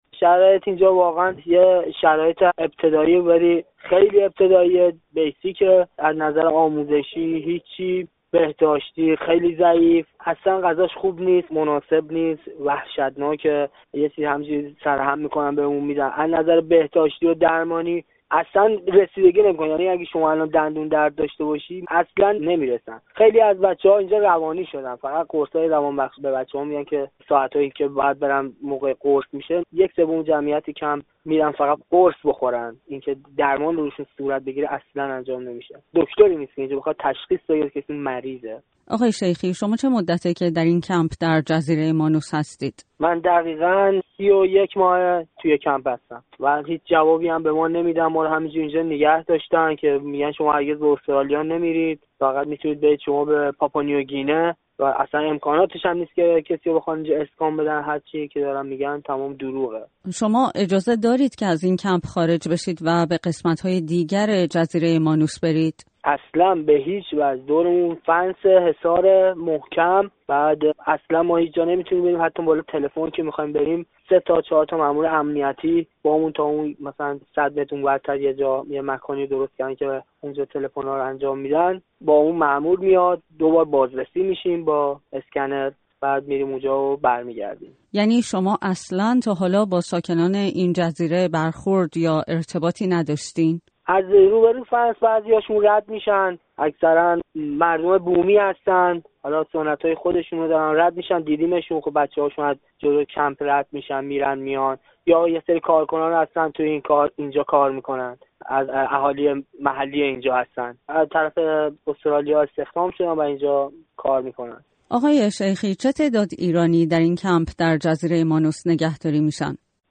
گفت‌وگوی
با یک پناهجوی ایرانی در کمپ مانوس